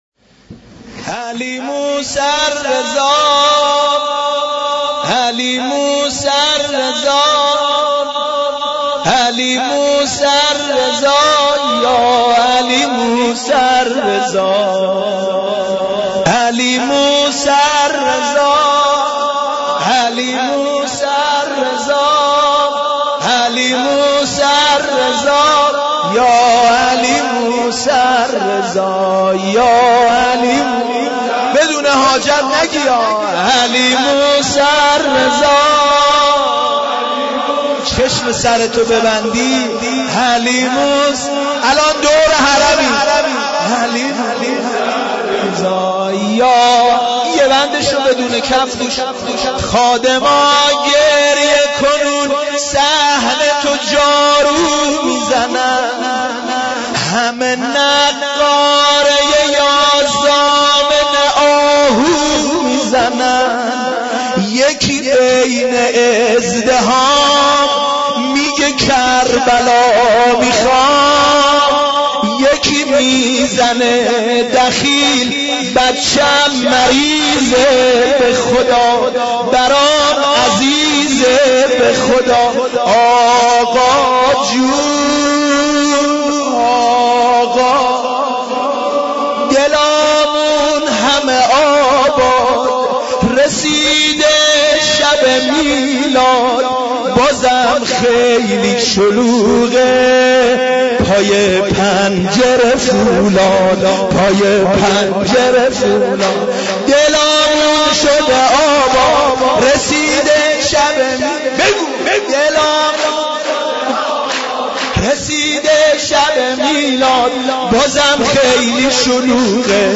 صوت/مدح و سرود میلاد امام رضا (ع)